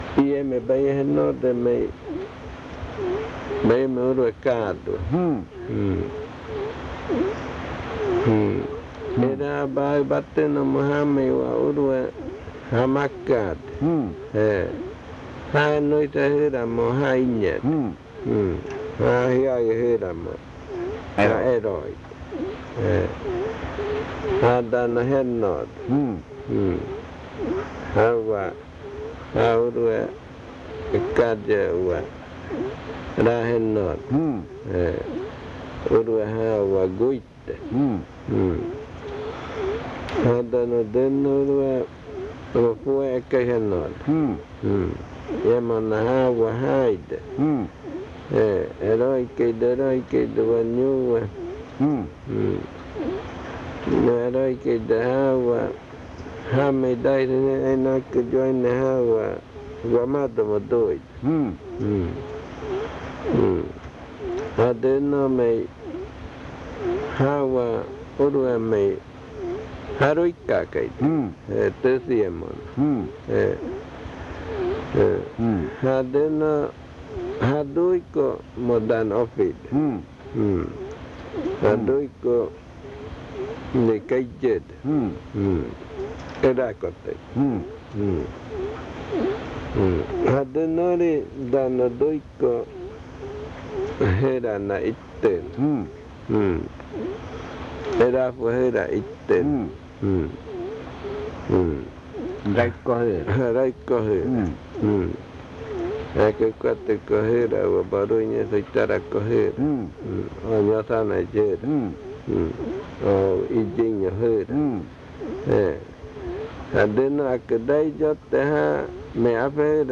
Adofikɨ (Cordillera), río Igaraparaná, Amazonas
Esta es una breve explicación y el canto de la conjuración de calentura del niño.
This is a brief explanation and the chant of the child's fever spell. This spell is for children who are already walking.